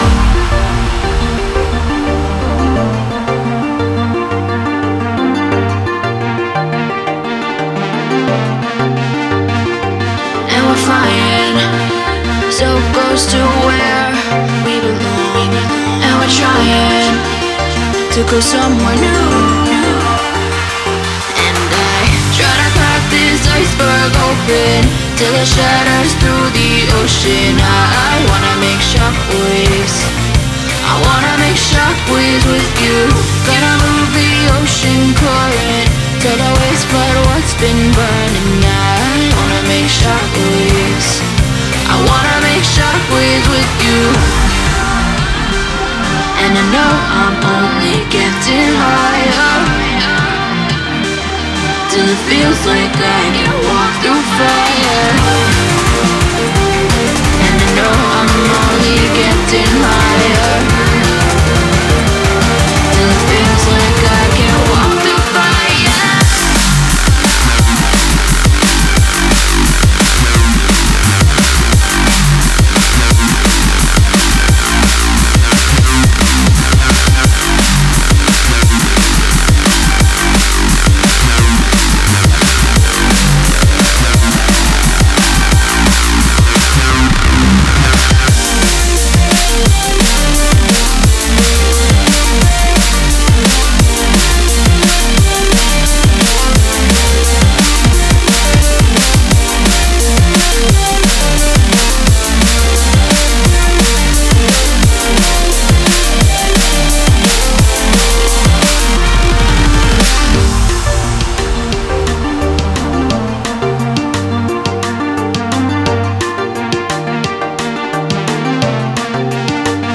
Стиль: Drum & Bass